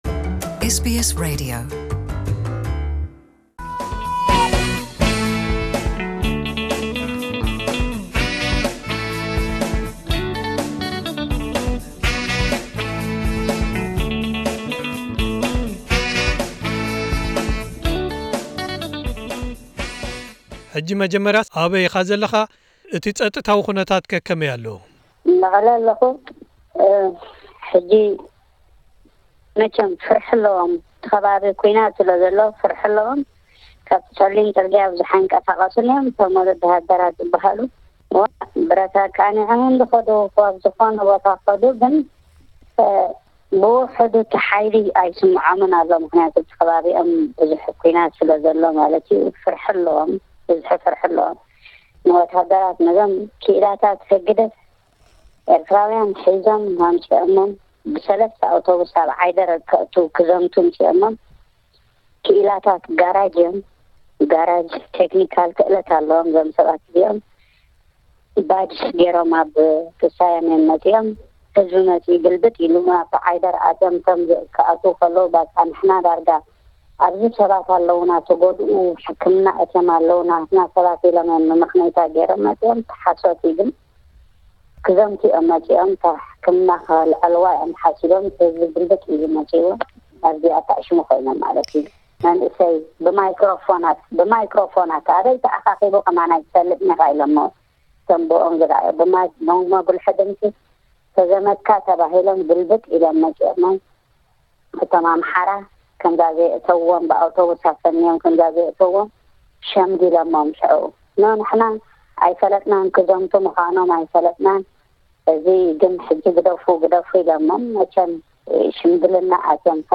ክቡራት ሰማዕትና ኩነታት ኤርትራውያን ስደተኛታት ኣብ ትግራይ ኣብ ኣሻቓሊ ኩነታት ይርከብ ኣሎ። ኩነታቶም ንምፍላጥ ኣብ ዝፈተንናሉ ምስ ሓደ ካብ ኣብ ከተማ መቐለ ምስ ስድርኡ ኣብ ስግኣት ኣለኹ ዝበለና ኤርትራዊ ስደተኛ ብስልኪ ተራኺብና። እቲ ዘለውዎ ከቢድ ኩነታት (ብኻልእ ሳልሳይ ኣካል ምርግጋጹ ኣጸጋሚ'ኳ እንተኾነ) ዝሓልፍዎ ዘለው ተሞክሮን ኣብ'ቲ ኩነታት ዘለዎም ርድኢትን ስለዝኾነ እቲ ድምጹ ቁሩብ ቀይርናን ስሙ ዓቂብናን ናባኹም ነቕርቦ ኣለና።